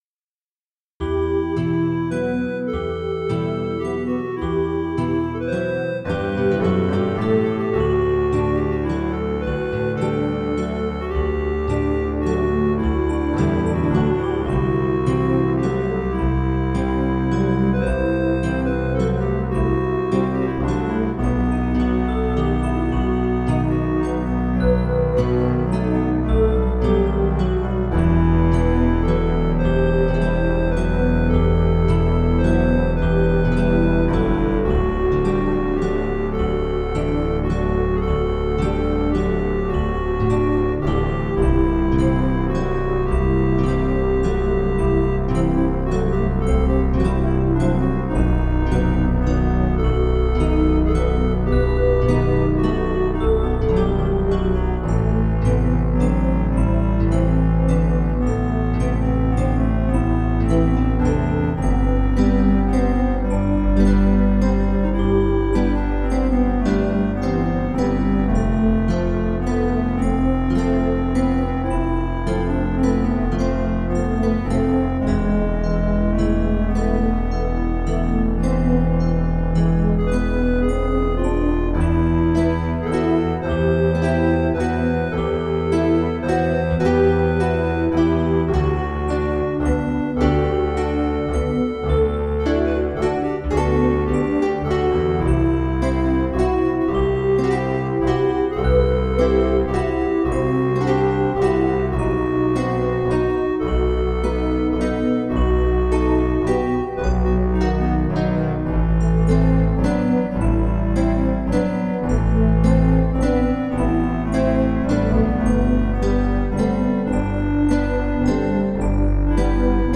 gothic, folk and rock
Recorded digitally, using a Sound Blaster Audigy 2 SE.